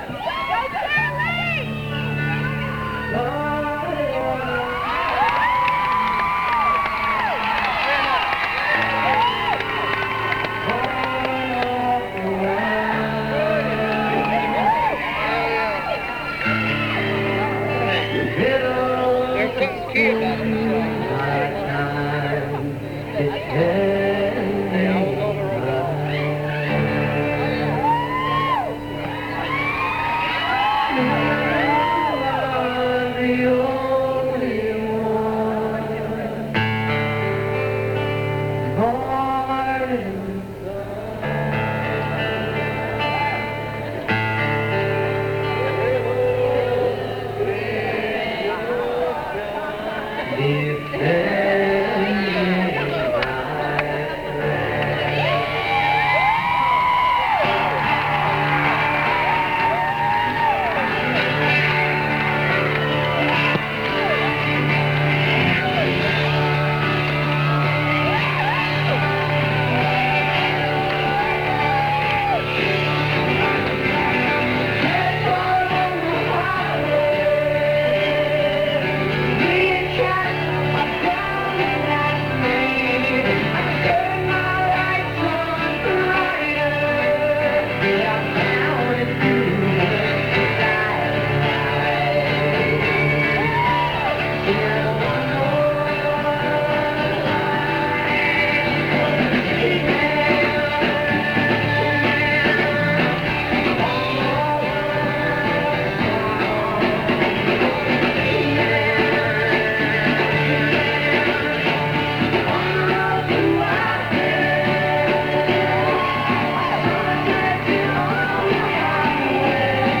(incomplete, band show)